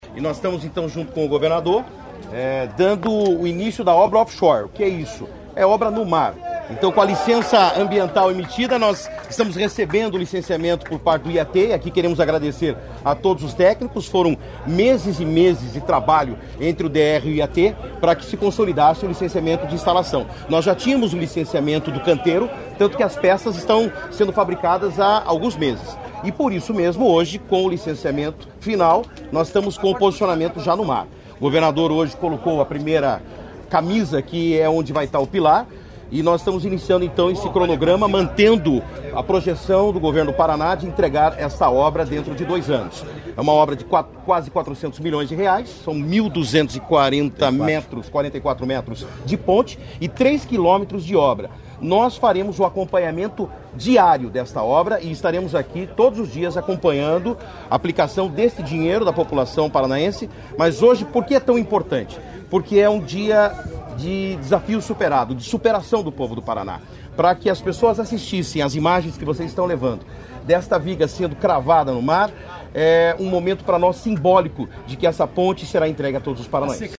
Sonora do secretário Estadual de Infraestrutura e Logística, Sandro Alex, sobre a liberação das obras da ponte de Guaratuba